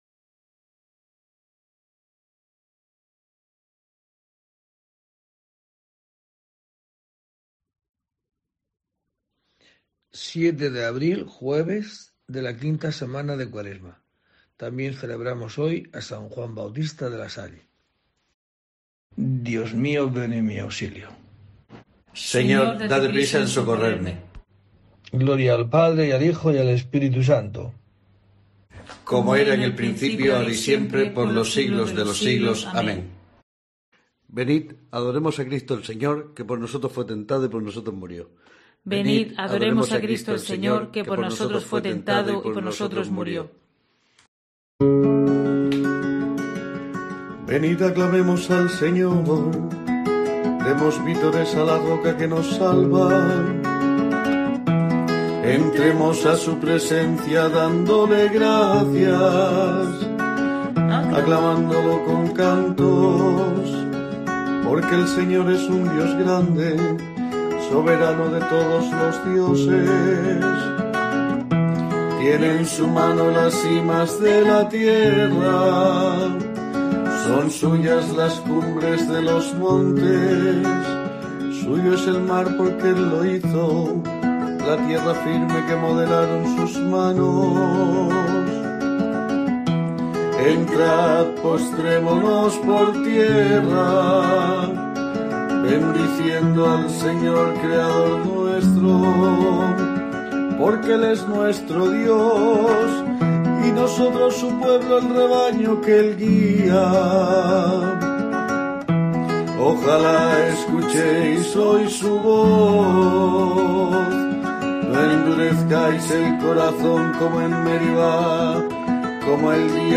07 de abril: COPE te trae el rezo diario de los Laudes para acompañarte